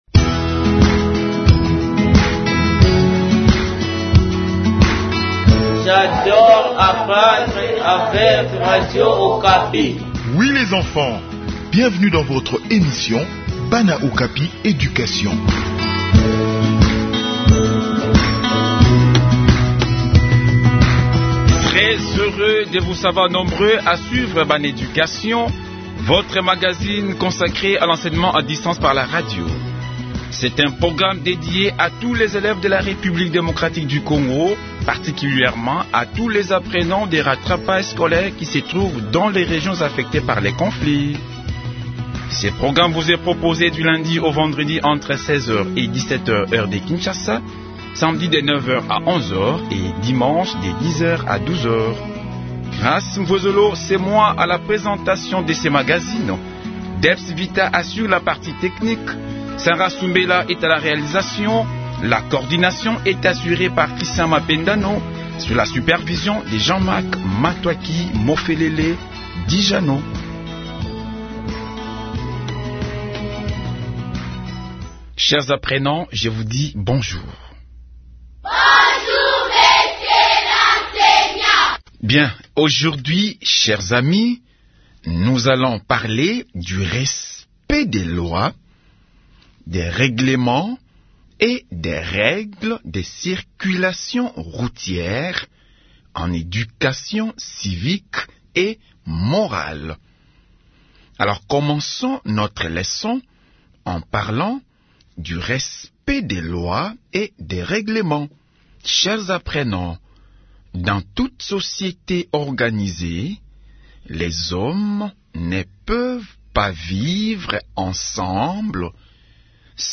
Enseignement à distance : leçon sur la règlementation de la circulation routière